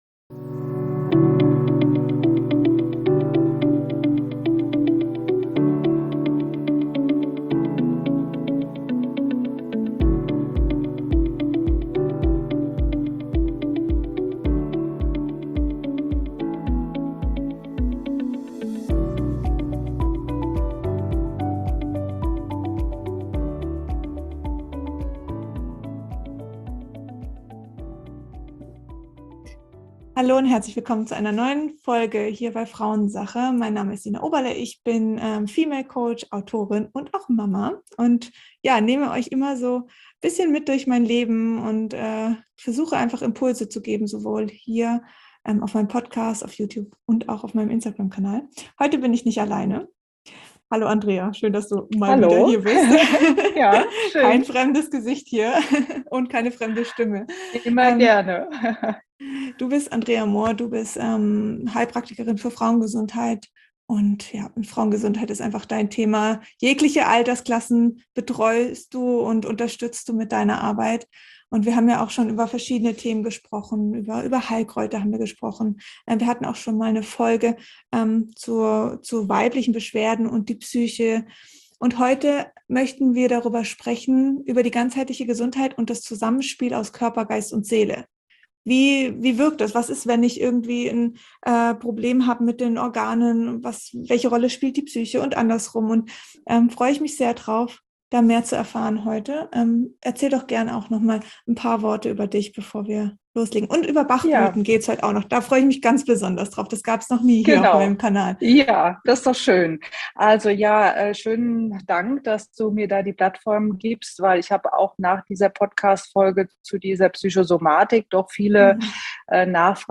Wie sieht ein Alltag einer Mama aus, deren Kinder nach dem "freien Lernen" lernen? Dieses Interview ist für all die Mamas, die kein gutes Gefühl haben ihre Kinder in das bestehende Schulsystem zu bringen.